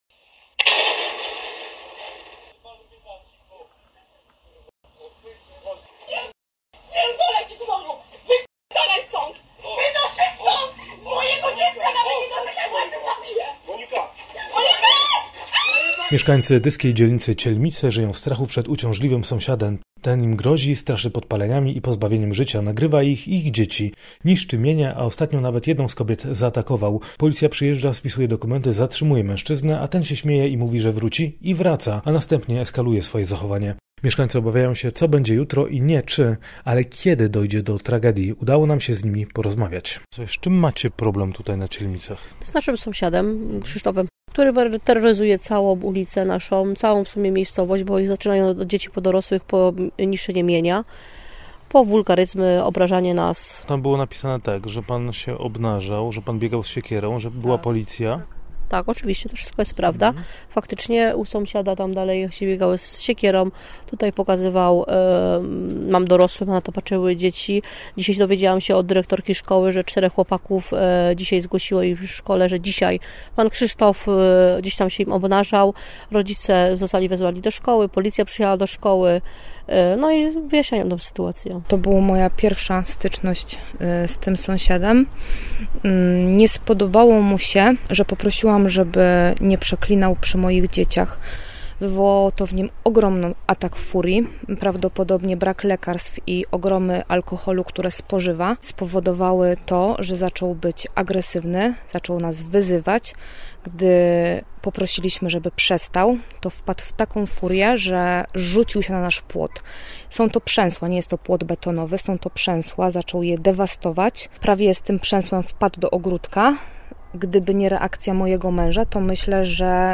Posłuchajcie, co powiedzieli nam mieszkańcy: